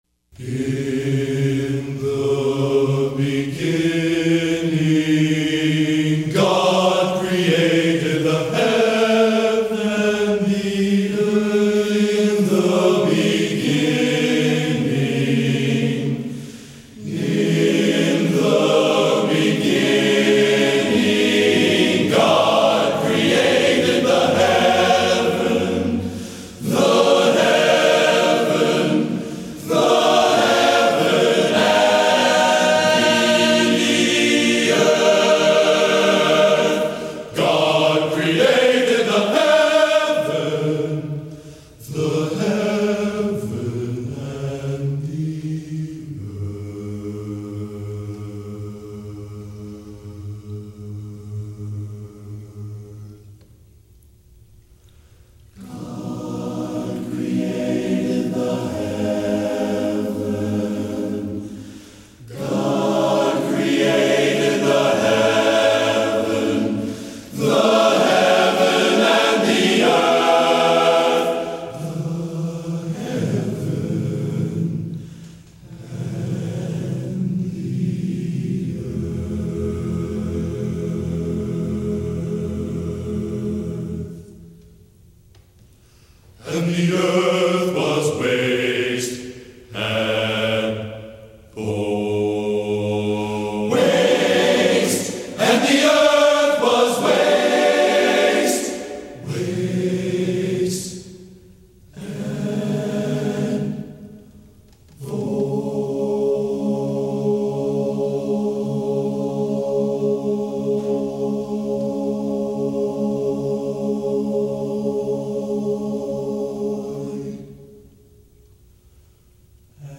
Genre: Sacred | Type: